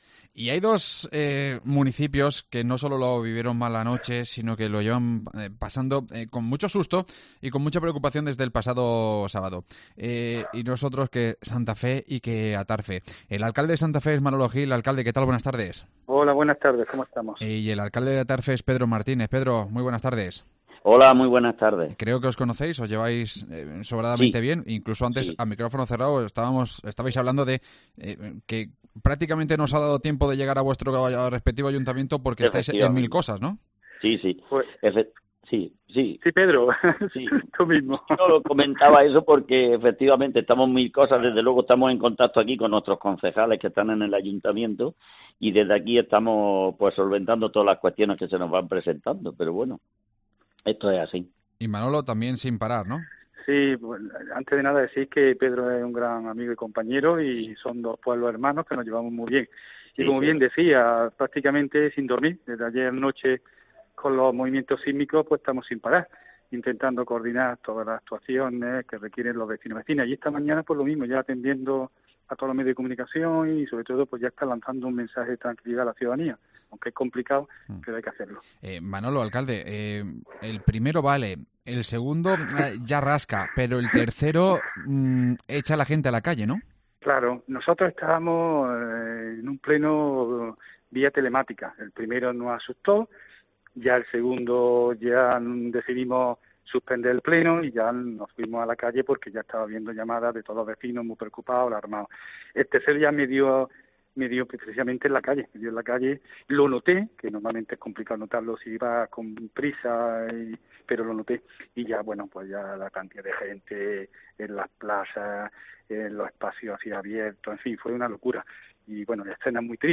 Los alcaldes de Santa Fe y Atarfe se unen en directo en COPE